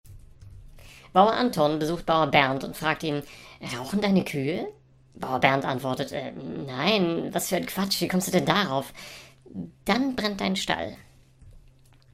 Vorgetragen von unseren attraktiven SchauspielerInnen.
Comedy , Unterhaltung , Kunst & Unterhaltung